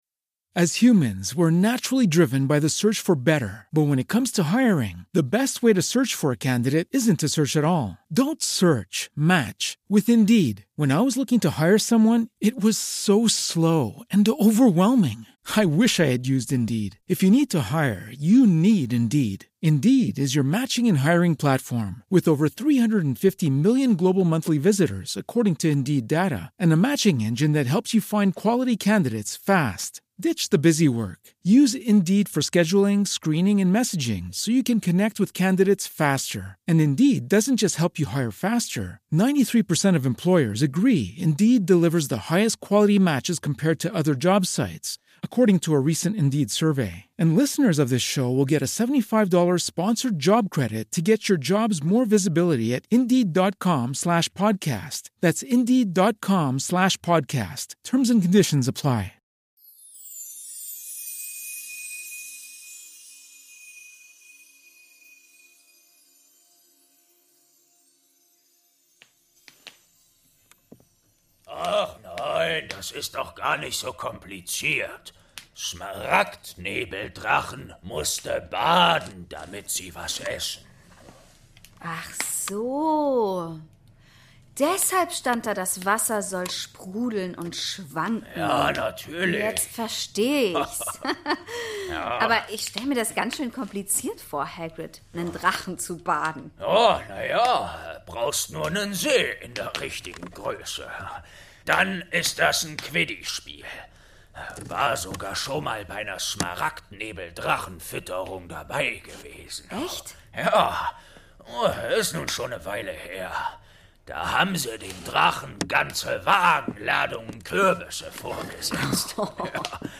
20. Türchen | Weihnachtskekse bei Hagrid - Eberkopf Adventskalender ~ Geschichten aus dem Eberkopf - Ein Harry Potter Hörspiel-Podcast Podcast